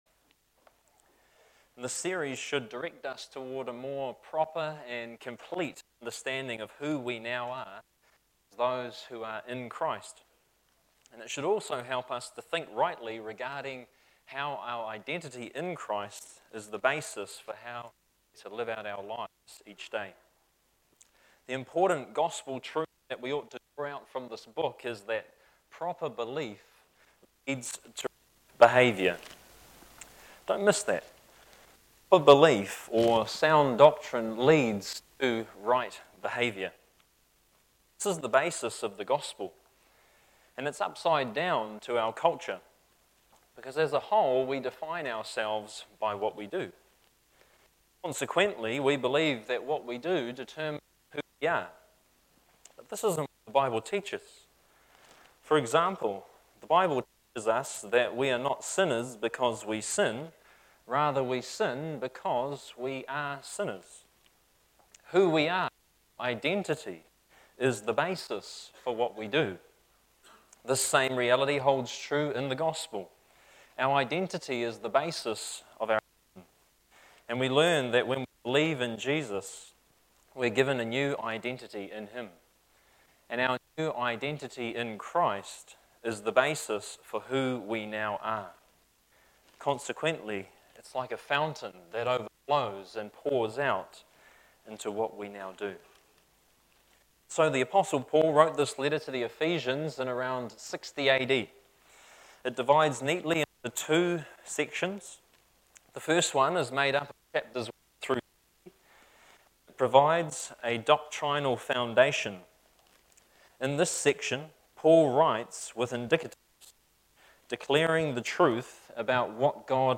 This recording cuts in and out at times during the first 5 minutes, but it is fine for the remainder of the message.